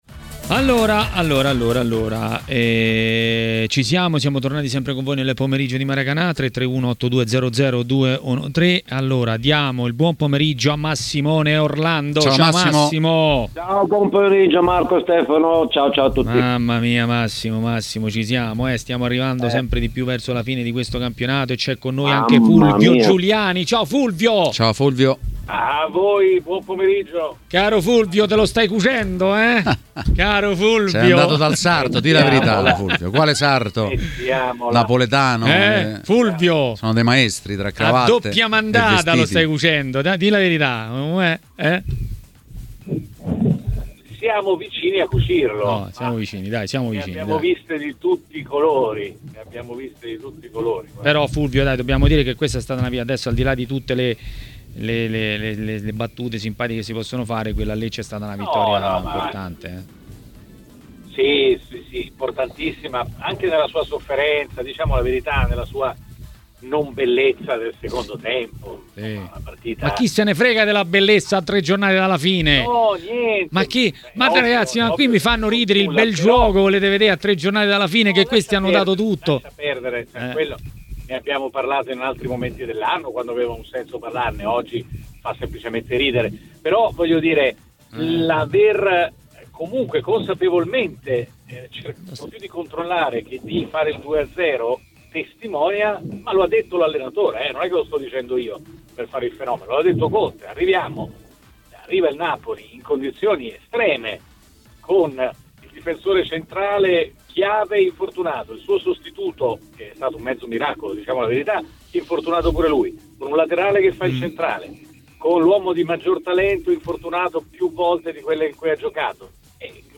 L'ex calciatore Massimo Orlando a Maracanà, nel pomeriggio di TMW Radio, ha parlato dei temi della Serie A.